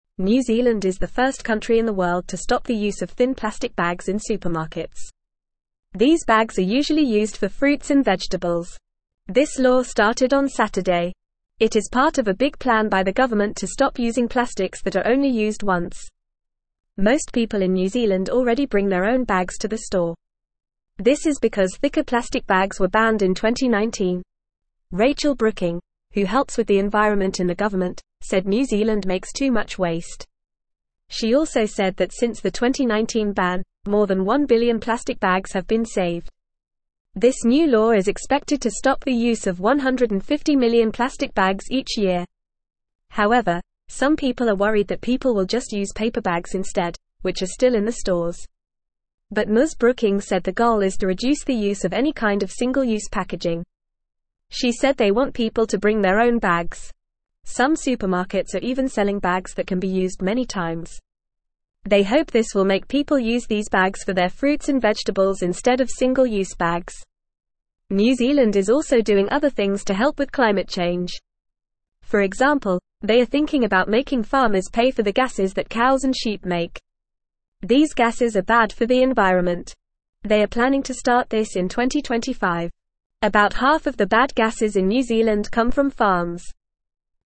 Fast
English-Newsroom-Beginner-FAST-Reading-New-Zealand-Stops-Using-Thin-Plastic-Bags.mp3